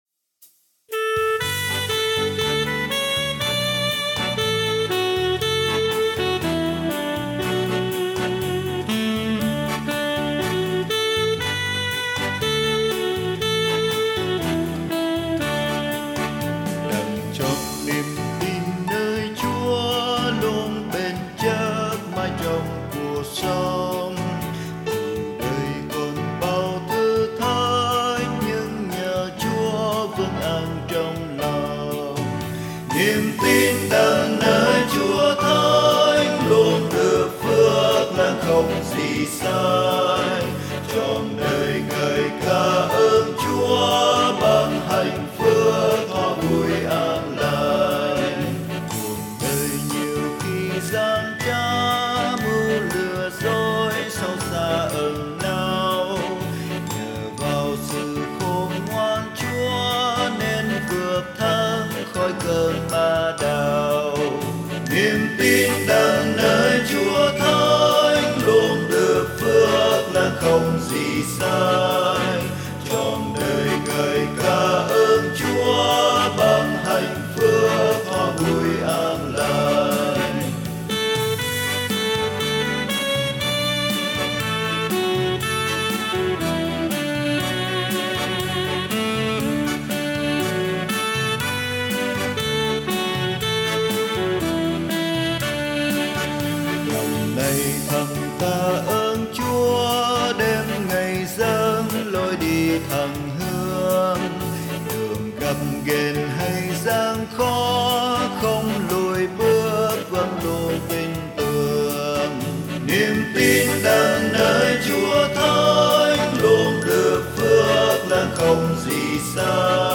Nhạc Thánh Sáng Tác Mới